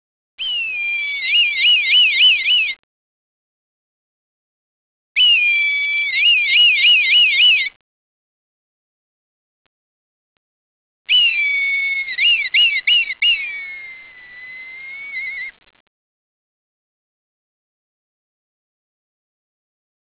El crit és una mena de miulo que emeten habitualment en presència d’altres exemplars o per foragitar altres espècies